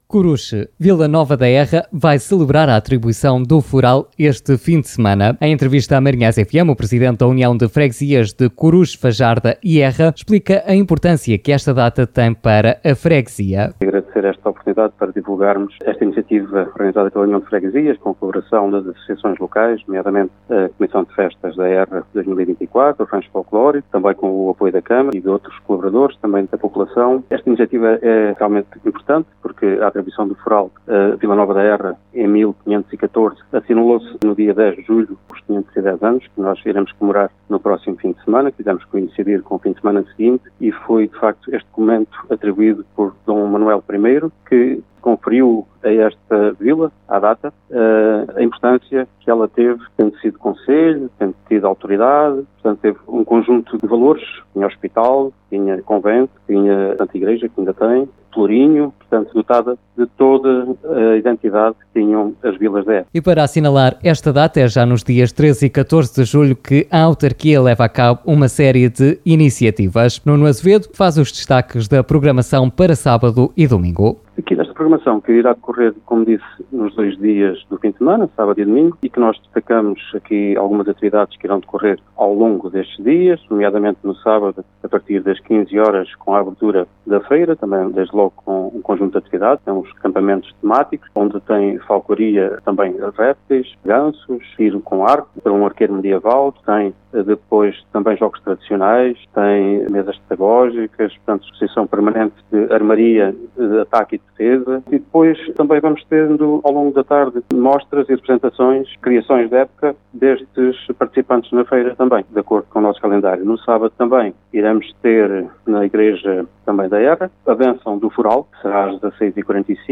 Escute, aqui, a entrevista ao Presidente da União de Freguesias de Coruche, Fajarda e Erra, Nuno Azevedo: